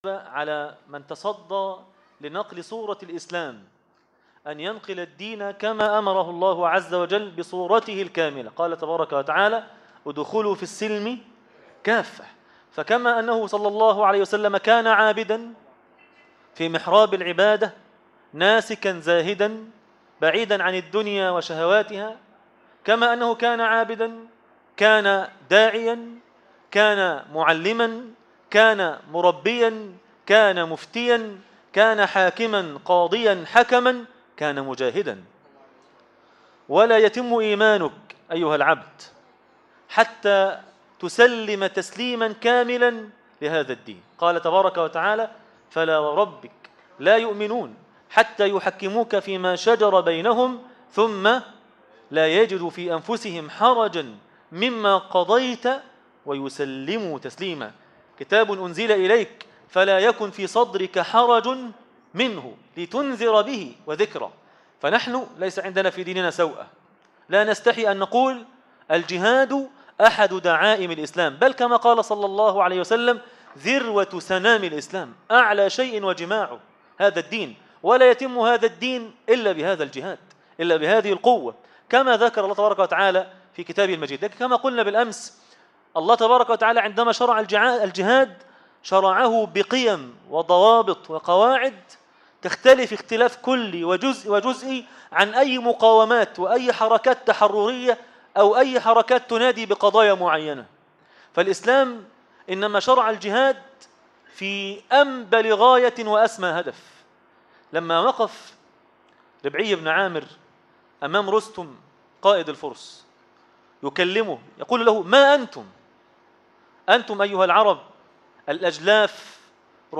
النبي (صلي الله عليه وسلم ) مجاهدا -الجزء الثاني - درس التراويح